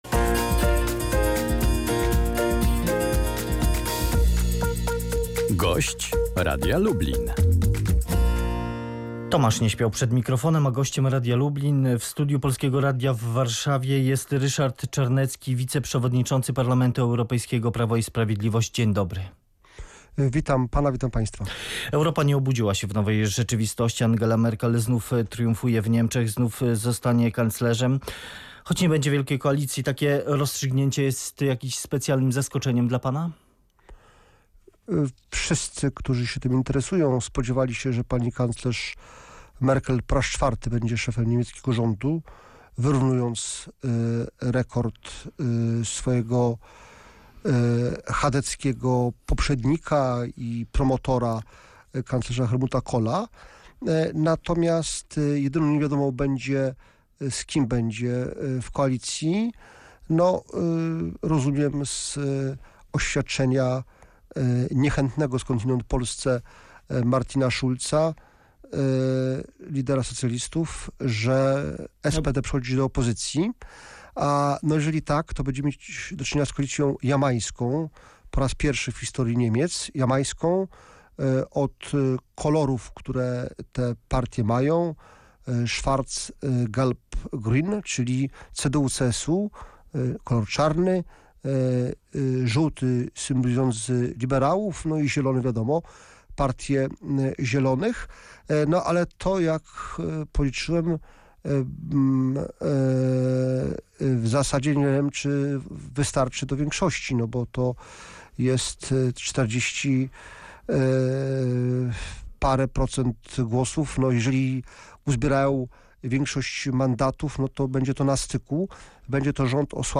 Wyniki wyborów oraz ich znaczenie dla Polski komentował Ryszard Czarnecki, wiceprzewodniczący Parlamentu Europejskiego, polityk Prawa i Sprawiedliwości.